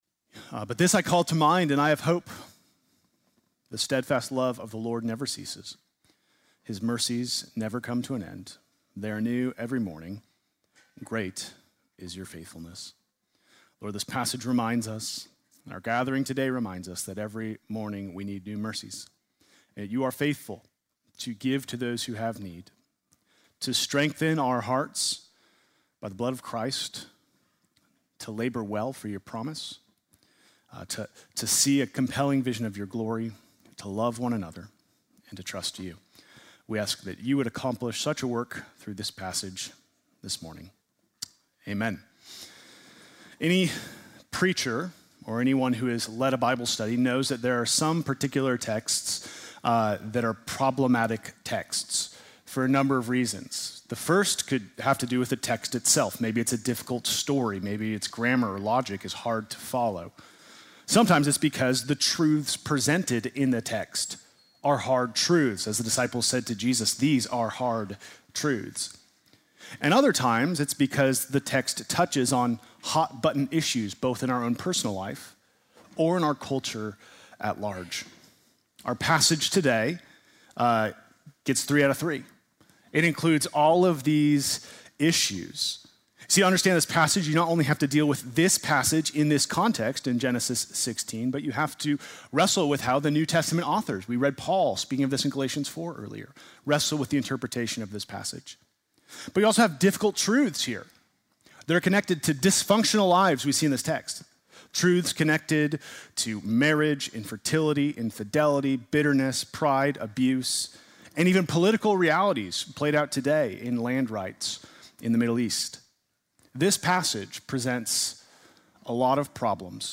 Sunday morning message February 22